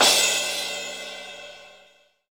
Crashes & Cymbals
pcp_crash02.wav